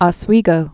(ŏs-wēgō)